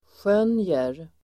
Uttal: [sj'ön:jer]